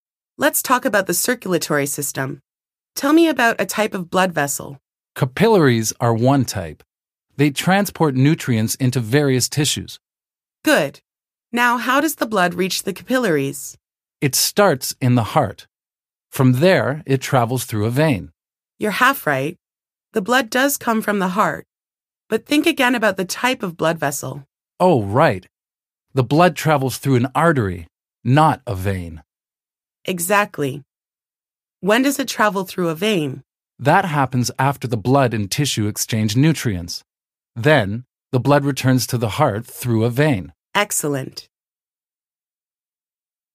Topic_07_Conversation.mp3